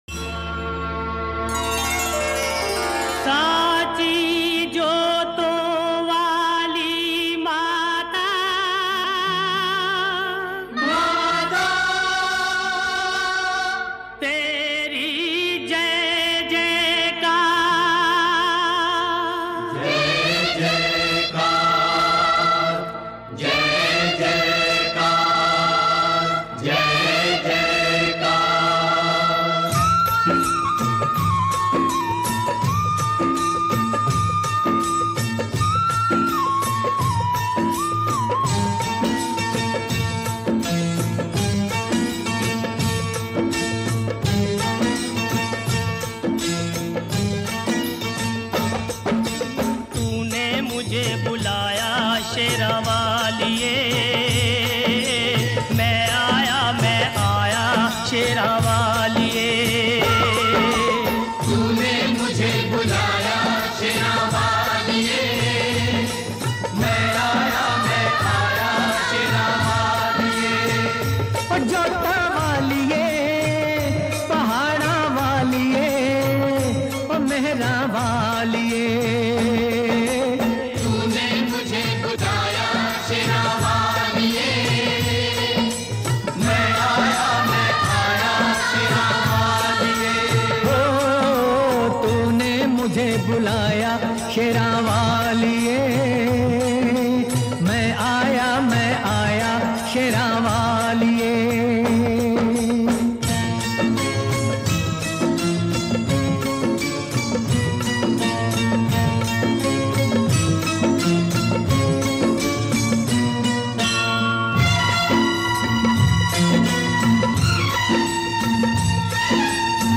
भजन